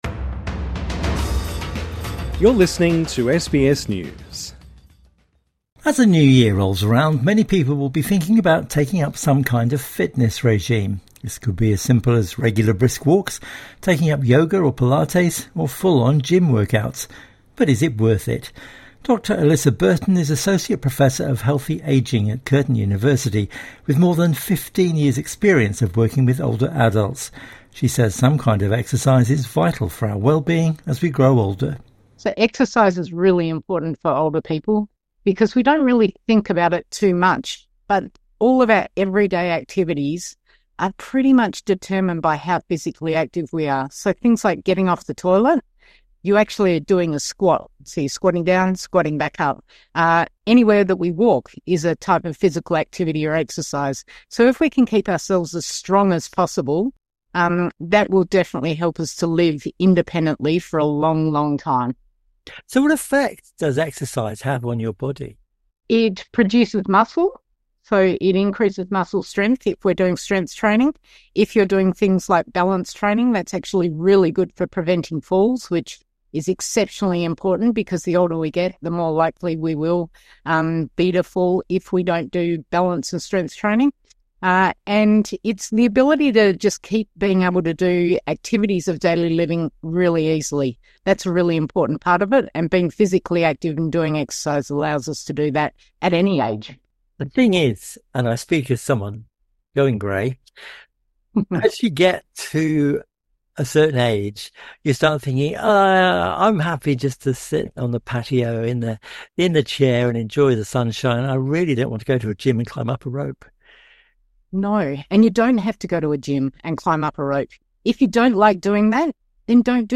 INTERVIEW: Living better, living longer